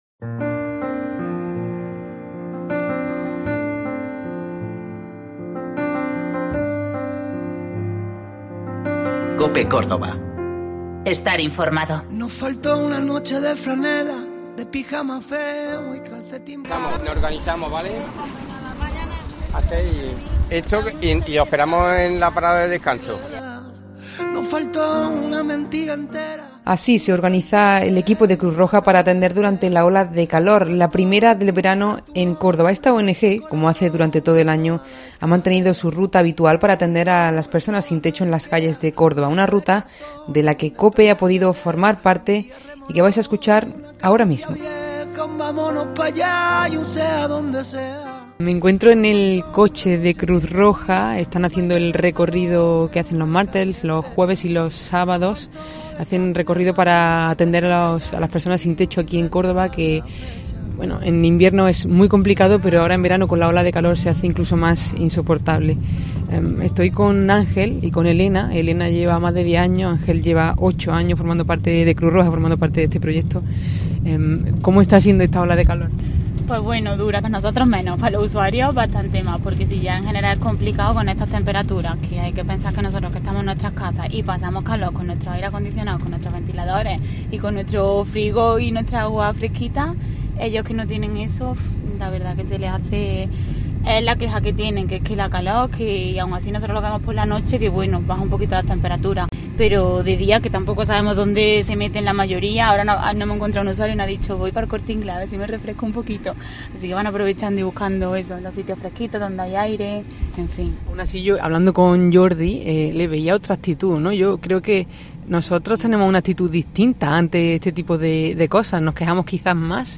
COPE se ha adentrado en la ruta que hace Cruz Roja cada noche de martes, jueves y sábados para conocer de primera mano la situación de las personas sin hogar, sus inquietudes y la forma en que lidian con una de las olas de calor más complicadas de la península.